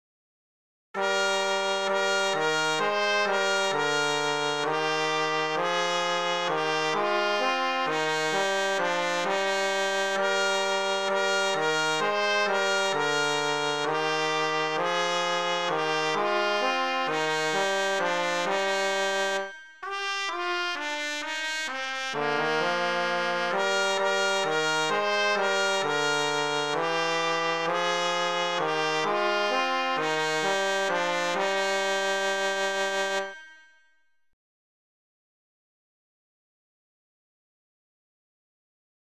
Traditional melody